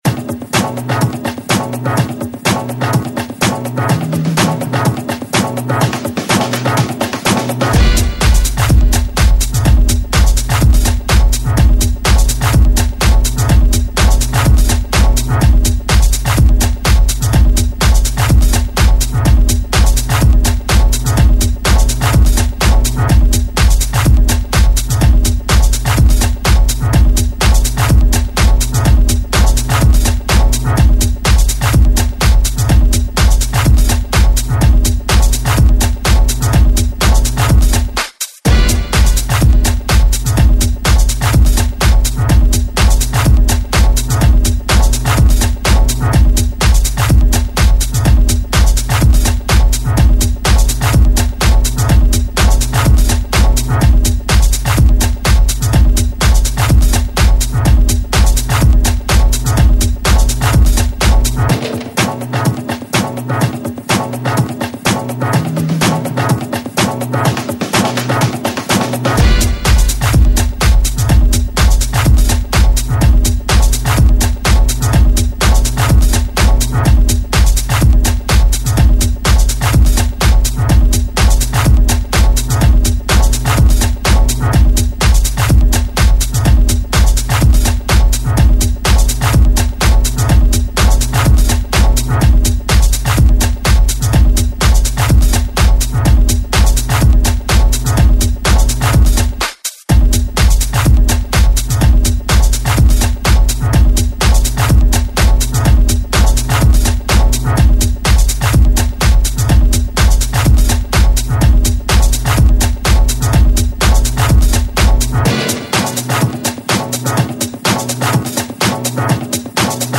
drum-laden